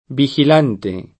vigilante [viJil#nte] s. m. («sorvegliante»); pl. (raro) -ti — sp. vigilante [